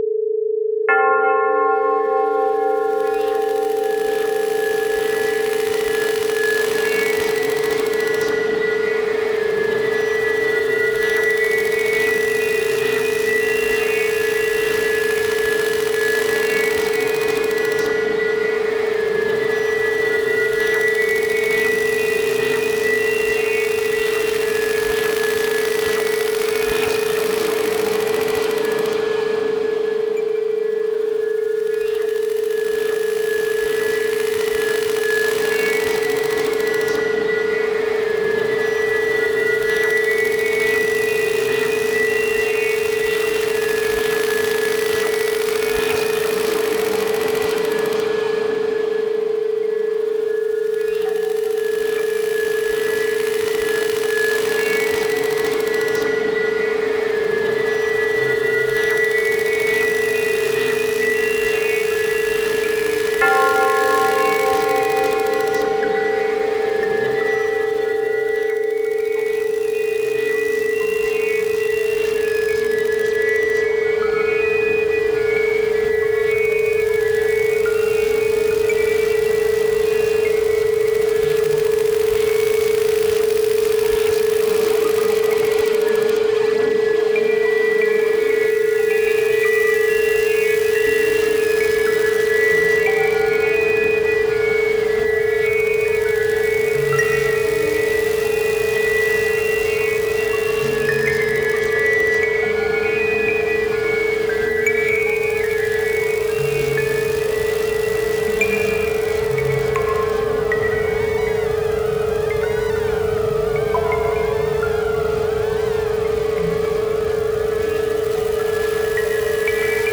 ある種のミニマル・アンビエント系の様相でありながら、総時間189分（3x3x3x3x7）をかけて、
更にこれら42曲は、特別な音響的デザインにより、ディープなバイノーラル・ビーツ体験をもたらす。
バイノーラル・ビーツの為の正弦波と、教会の鐘の音のサンプリングを除き、
総ての音はギター起源で録音されている。